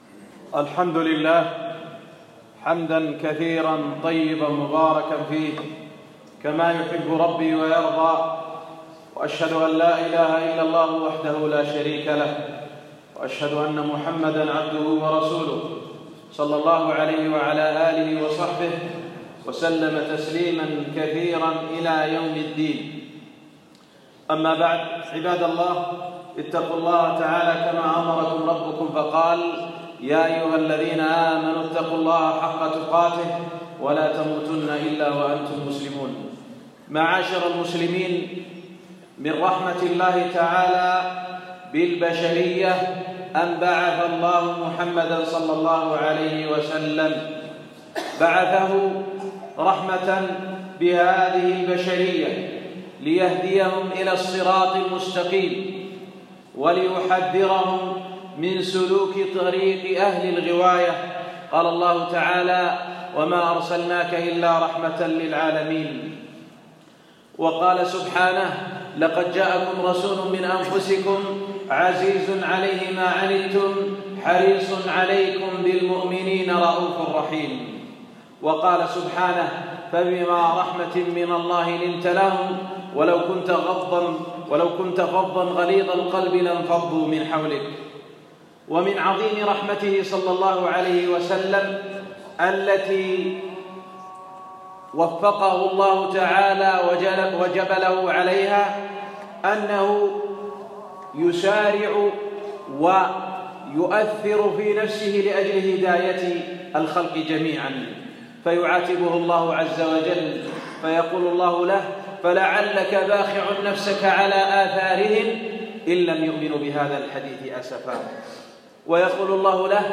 خطبة - من جوامع كلم النبيﷺ